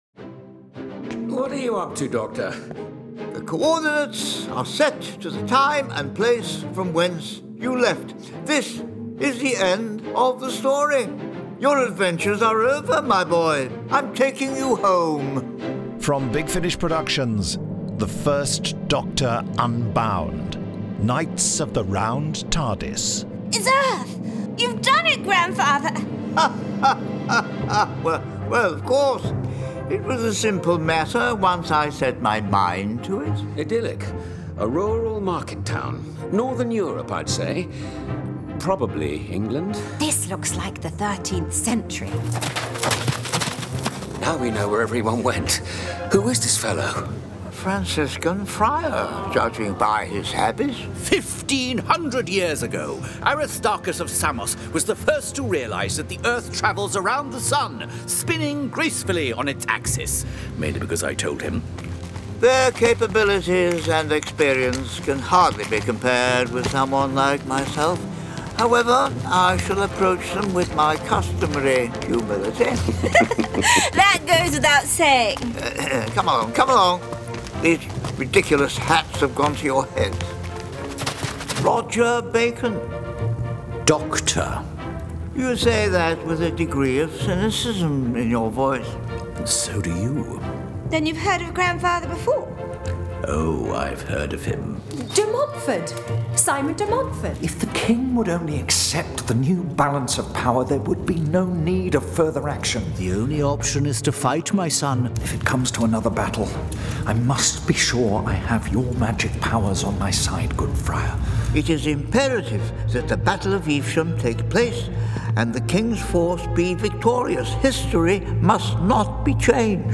Award-winning, full-cast original audio dramas